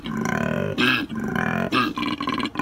Koala_Suara.ogg